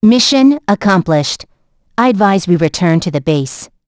Hilda-Vox_Victory.wav